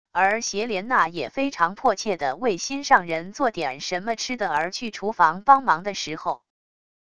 而叶莲娜也非常迫切的为心上人做点什么吃的而去厨房帮忙的时候wav音频生成系统WAV Audio Player